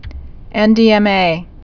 (ĕndēĕmā)